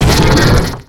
Cri de Gringolem dans Pokémon X et Y.